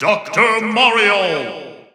The announcer saying Dr. Mario's name in English and Japanese releases of Super Smash Bros. 4 and Super Smash Bros. Ultimate.
Dr._Mario_English_Announcer_SSB4-SSBU.wav